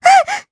Lilia-Vox_Damage_jp_02.wav